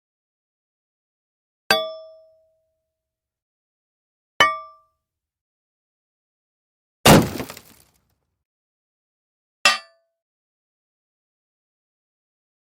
Metal Strikes
Metal Strikes is a free sfx sound effect available for download in MP3 format.
yt_ROeZMBEXwcU_metal_strikes.mp3